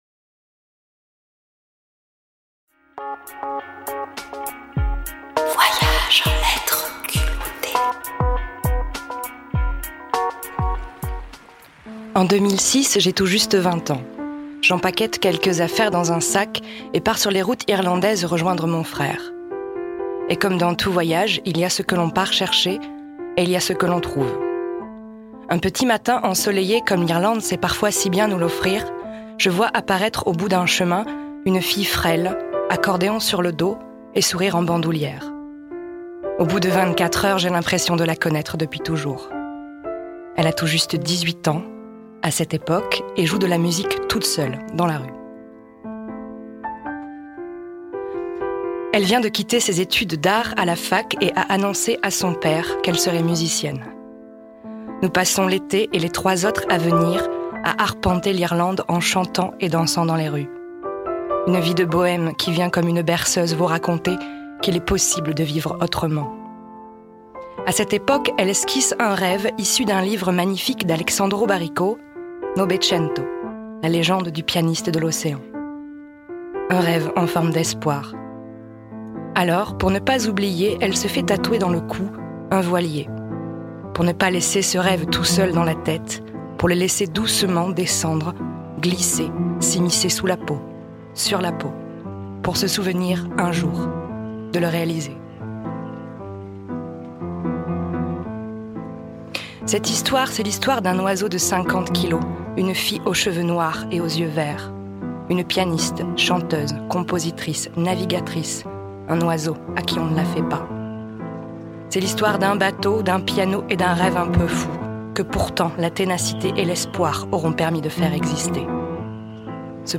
Pianocean – Chronique radio